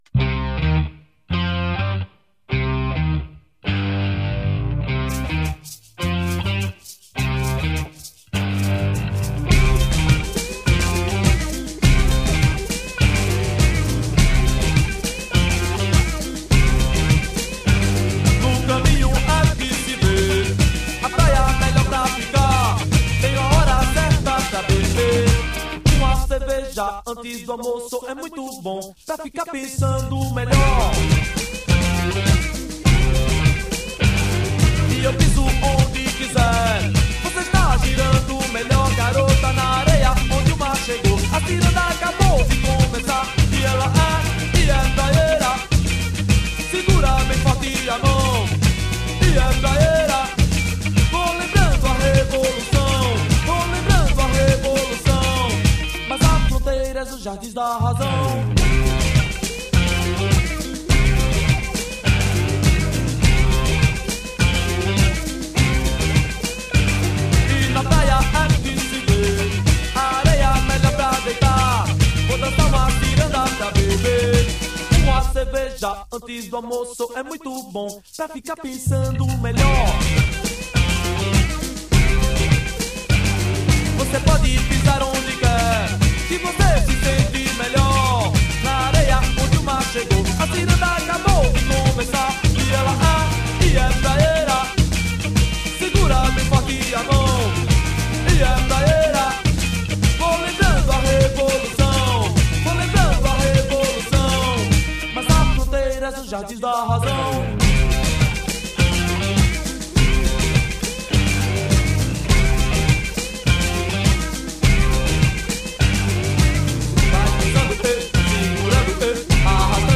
Viva o Manguebeat!